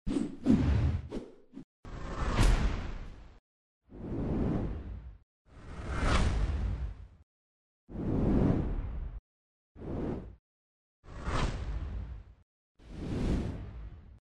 Эффект переключения слайдов